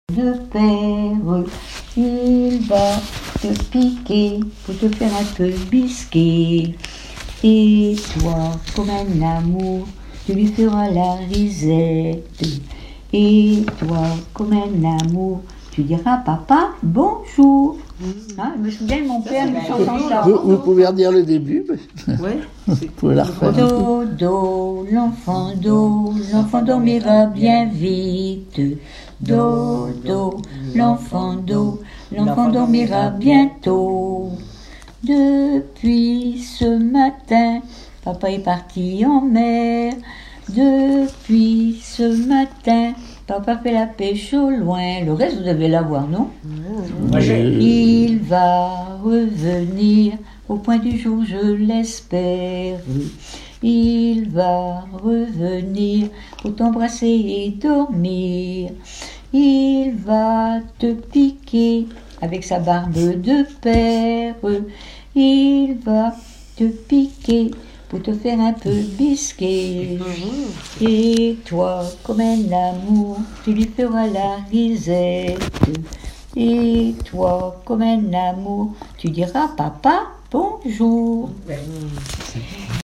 Douarnenez
enfantine : berceuse
Chansons populaires
Pièce musicale inédite